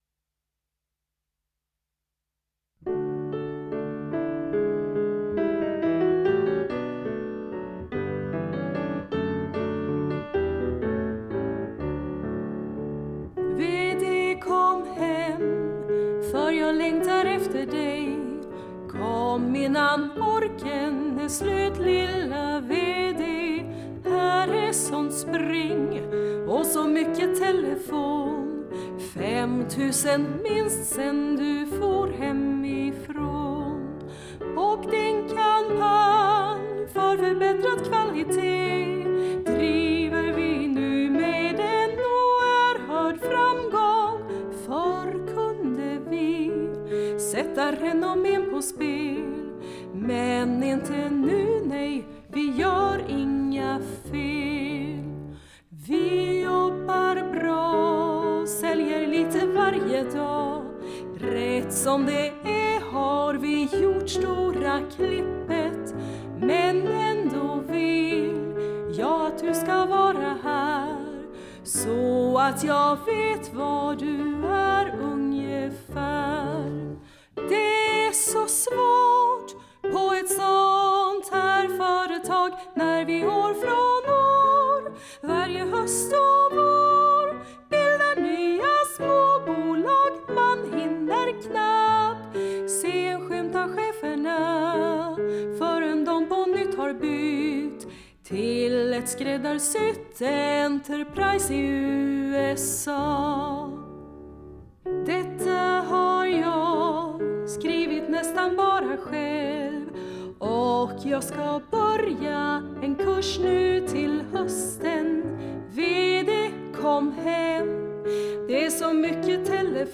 Musik och arrangemang och piano